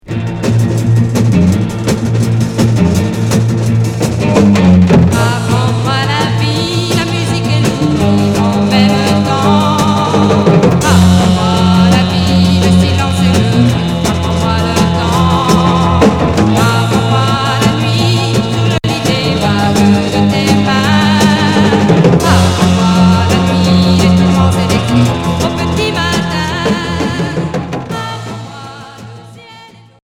Pop rock garage Unique 45t retour à l'accueil